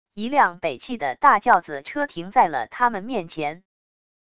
一辆北汽的大轿子车停在了他们面前wav音频生成系统WAV Audio Player